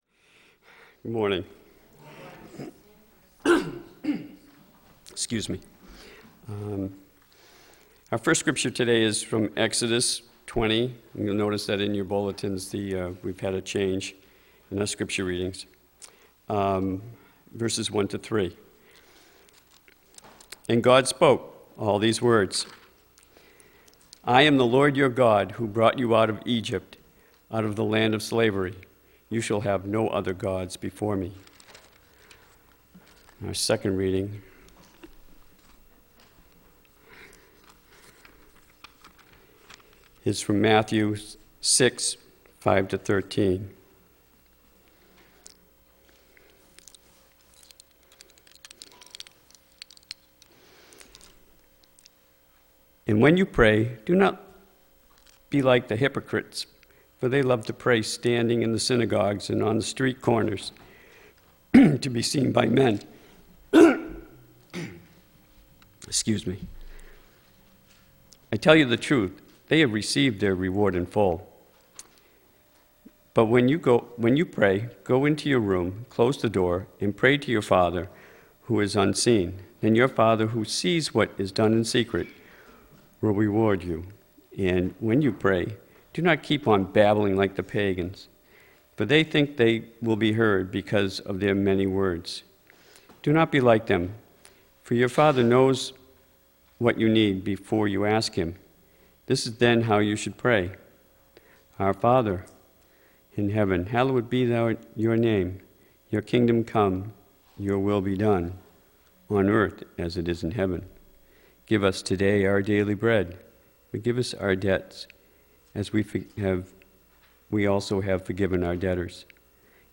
The audio recording of our latest Worship Service is now available.